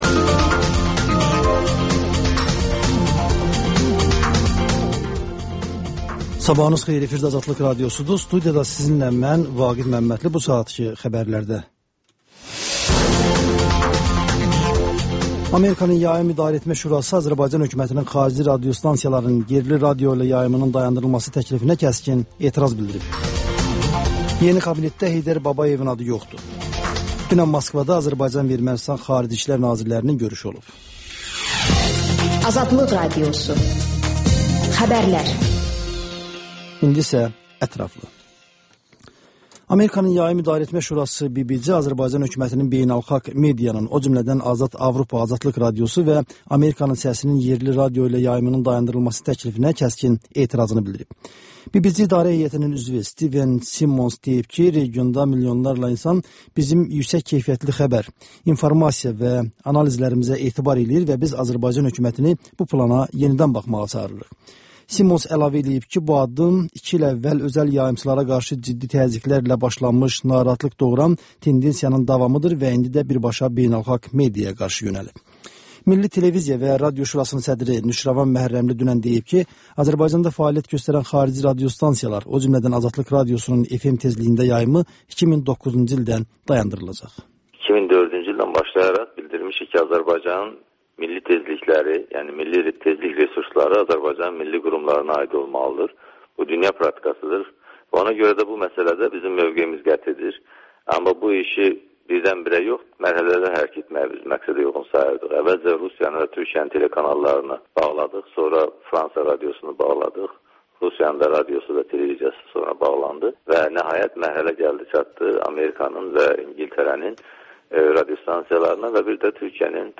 Xəbərlər, XÜSUSİ REPORTAJ: Ölkənin ictimai-siyasi həyatına dair müxbir araşdırmaları və TANINMIŞLAR rubrikası: Ölkənin tanınmış simaları ilə söhbət